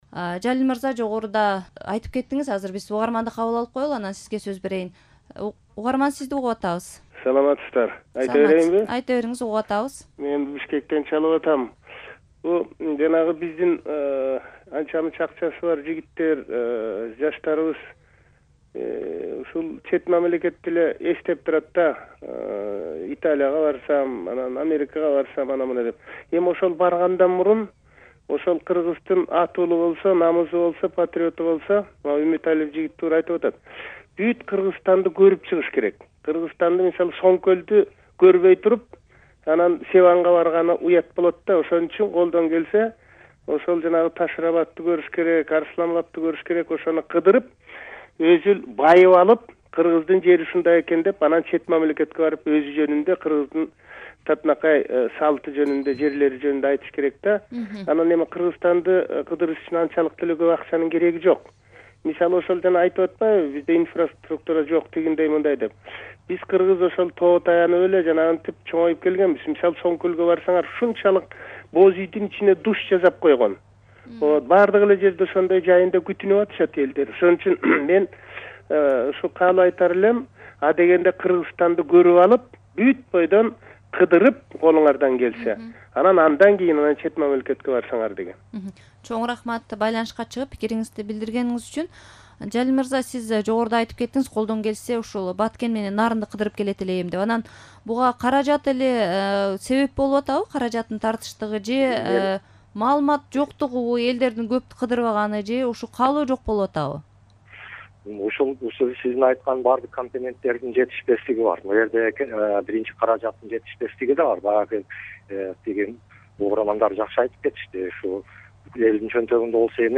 Эс алуу тууралуу талкуу (1-бөлүк)